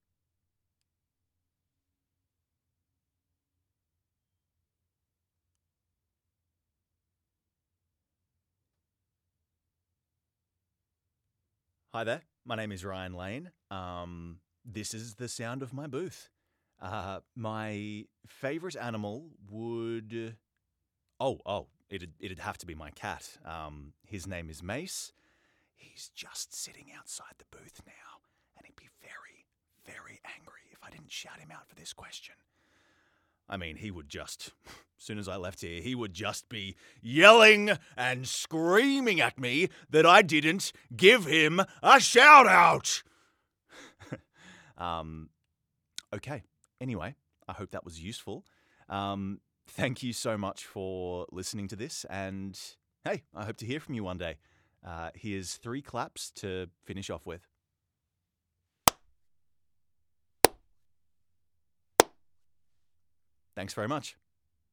Best Male Voice Over Actors In March 2026
Yng Adult (18-29) | Adult (30-50)